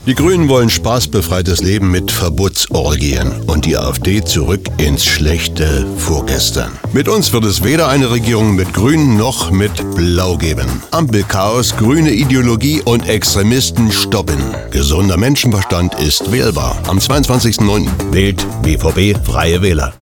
Unsere Radiospots:
04-bvb-fw-radiospot-afd-gruene-20sek.mp3